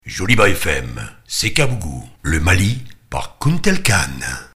Annonce Programme Radiophonique Joliba FM
Binthily Communication réalise des annonces audio courtes et percutantes pour promouvoir les programmes de Joliba TV et Joliba FM.